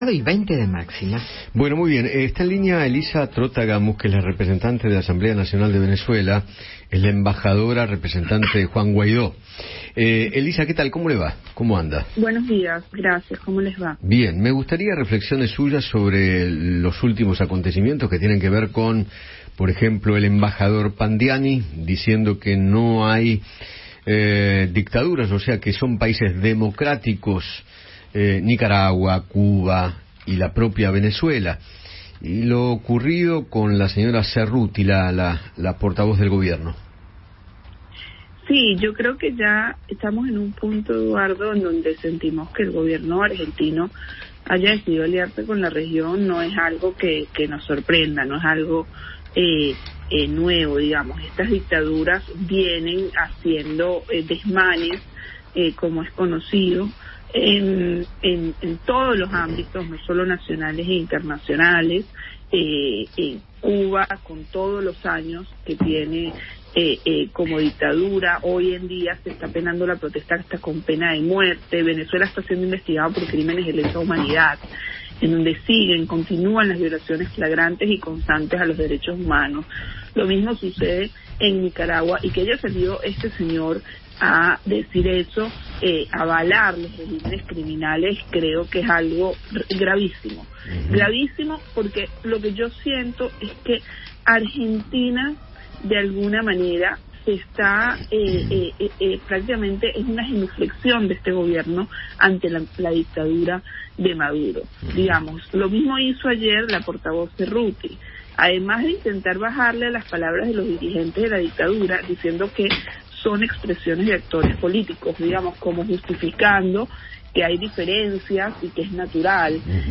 La representante de la Asamblea Nacional de Venezuela en Argentina, Elisa Trotta Gamus, charló con Eduardo Feinmann sobre el apoyo de la cancillería argentina a los regímenes de Venezuela, Nicaragua y Cuba, y acusó al Gobierno de Alberto Fernández de tener reverencia hacia Nicolás Maduro.